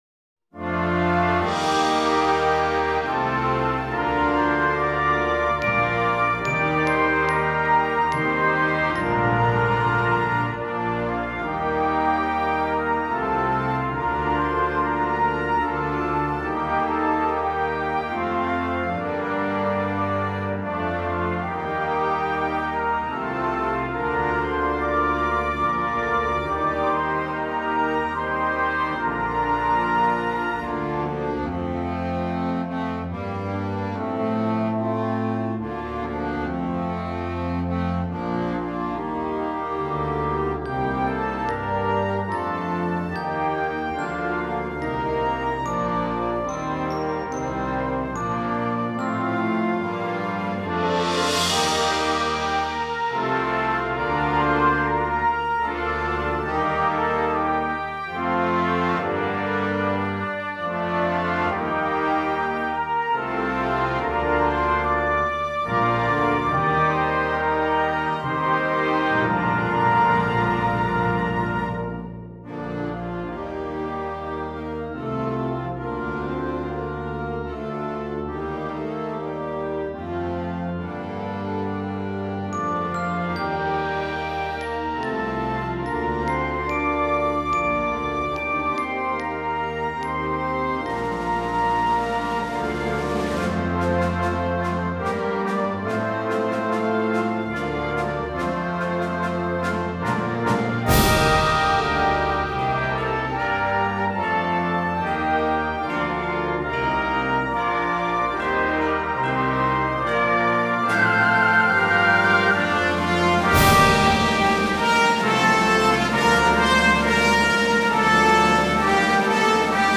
Gattung: Konzertante Blasmusik für Jugendblasorchester
Besetzung: Blasorchester
Diese stattliche Hymne